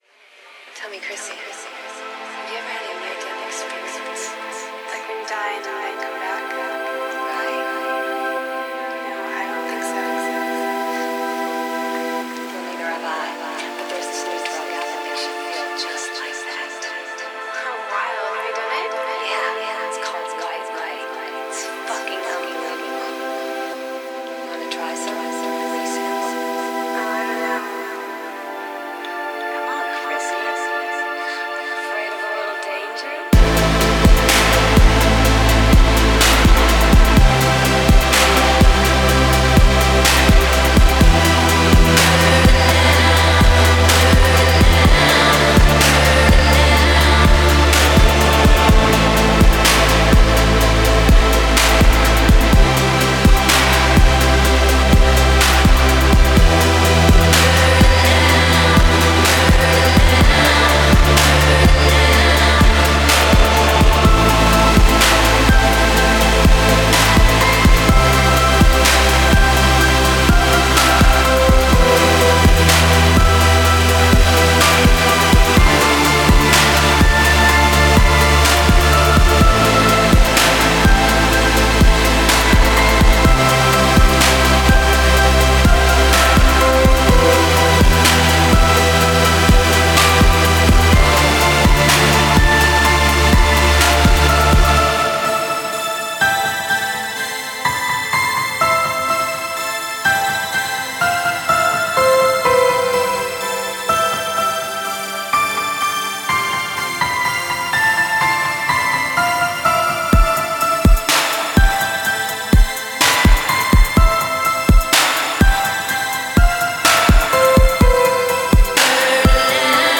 *фоновая композиция –